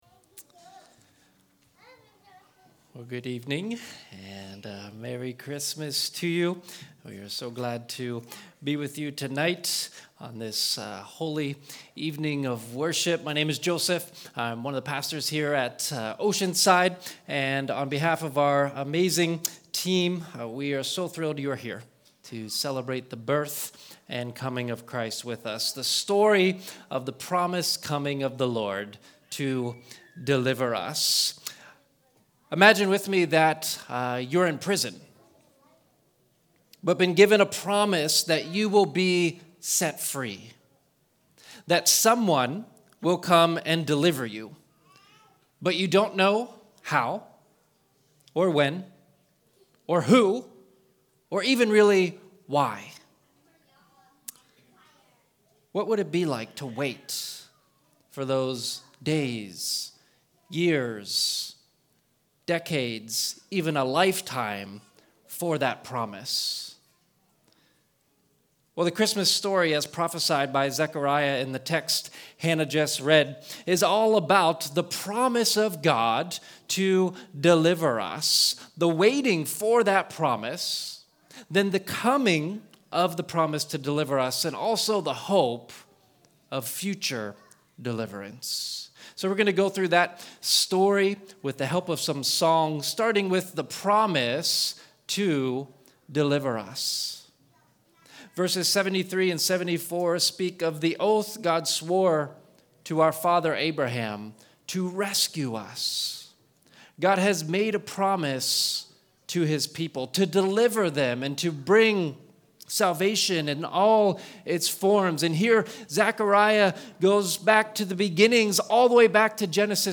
A special Christmas Eve message that explains God’s promise to Deliver Us through the birth of Christ.